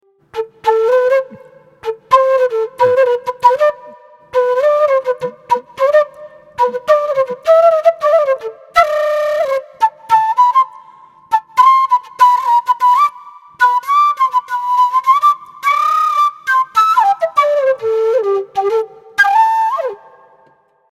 Кена (Quena, Amaru, G) Перу
Кена (Quena, Amaru, G) Перу Тональность: G
Кена - продольная флейта открытого типа, распространённая в южноамериканских Андах.
Материал: Diablo fuerte Wood (Podocarpus oleifolius wood).